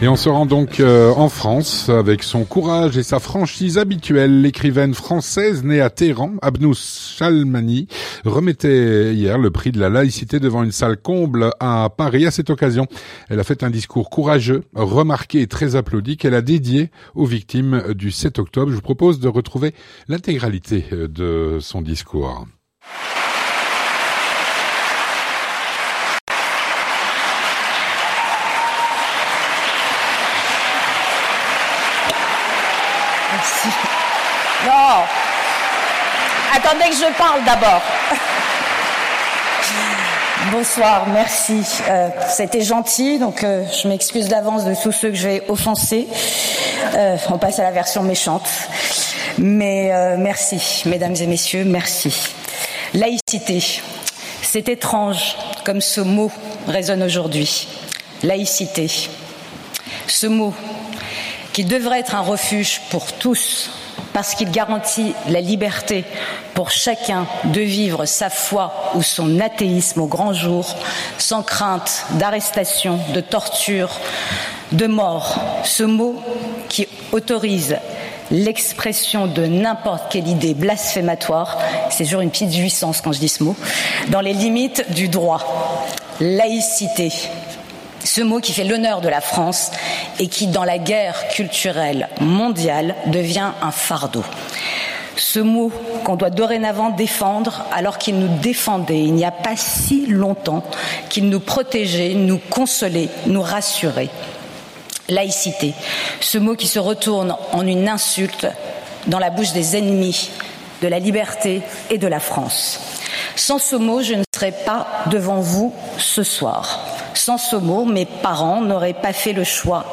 Vue de France - Un discours courageux lors de la remise du prix de la laïcité à Paris.
Avec son courage et sa franchise habituelle, l’écrivaine et journaliste française née à Téhéran, Abnousse Shalmani, remettait le prix de la laïcité devant une salle comble à Paris.
A cette occasion, elle a fait un discours courageux, remarqué et très applaudi, qu’elle a dédié aux victimes du 7 octobre ainsi qu'aux otages toujours détenus par les terroristes du Hamas.